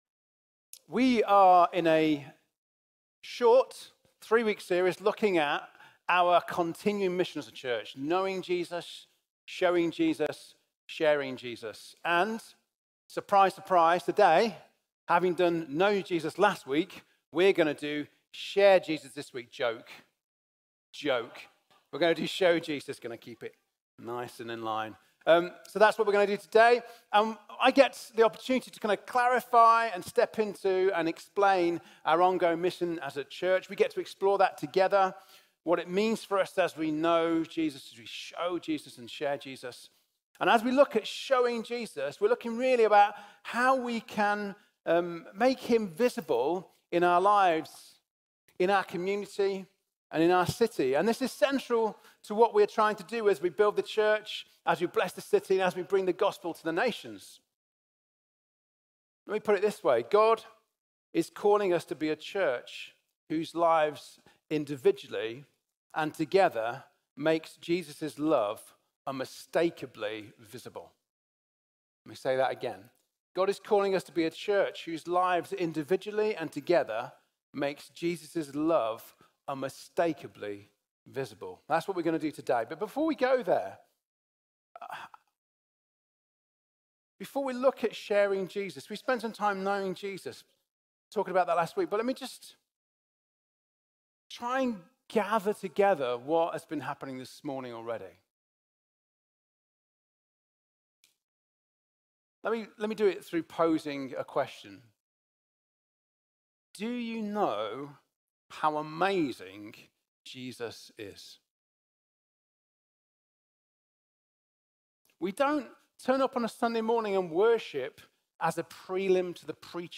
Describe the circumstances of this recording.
Download messages given at City Church Sheffield Sunday meetings and celebrations.